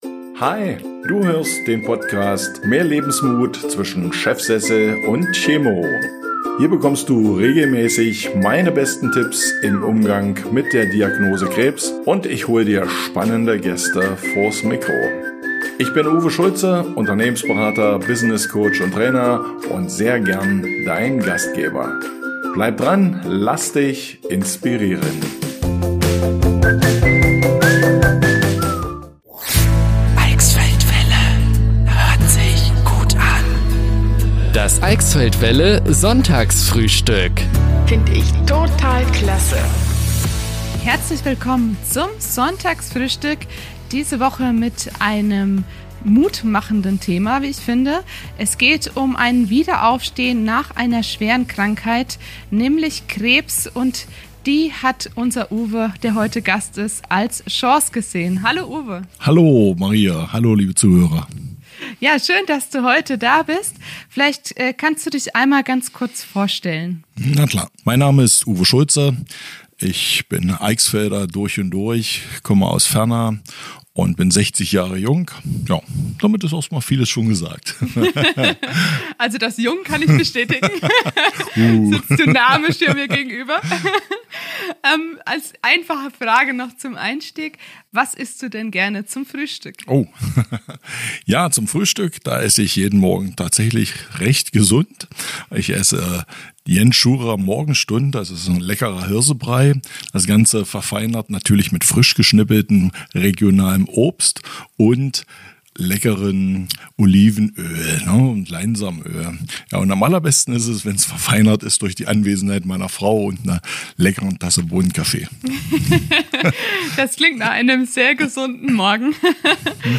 #002 Zu Gast im Radio Eichsfeld-Welle Sonntagsfrühstück am 13.05.2024 ~ Mehr Lebensmut zwischen Chefsessel und Chemo Podcast
‚Krebs als Chance’ ist das Thema im Sonntagsfrühstück bei Radio Eichsfeld-Welle am 13.05.2024.